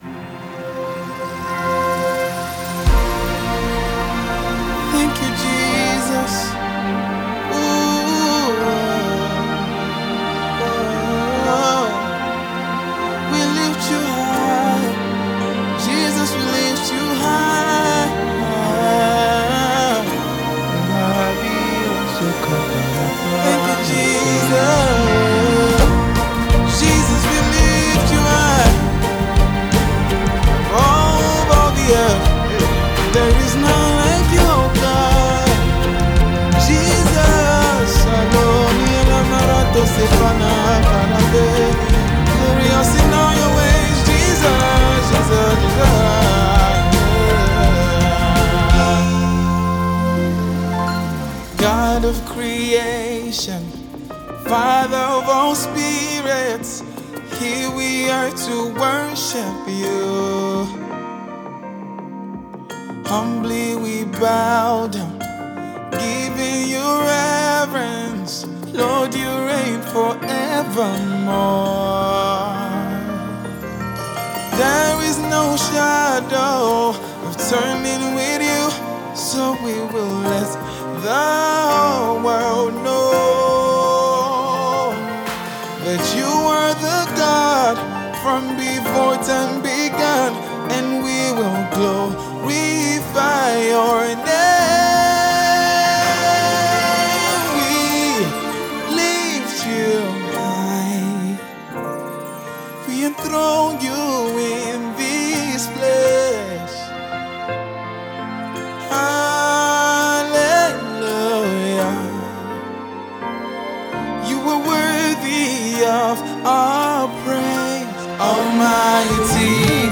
powerful sound of adoration